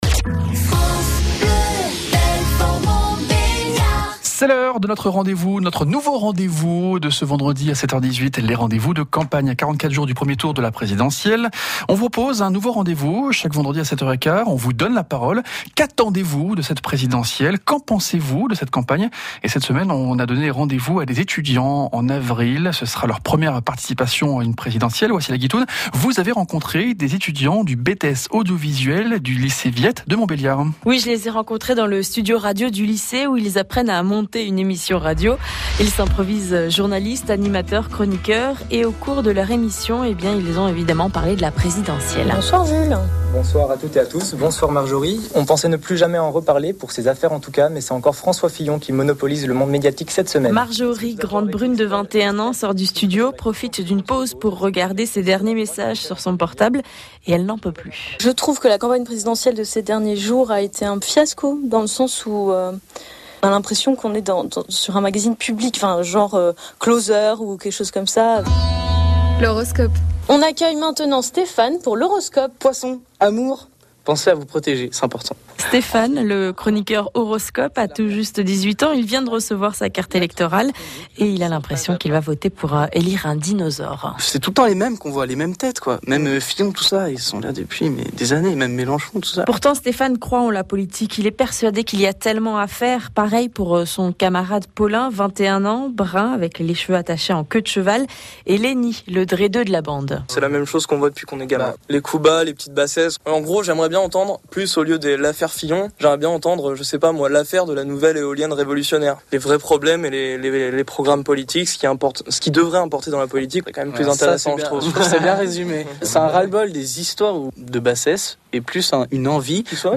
Très à l’aise, bravo aux interviewés !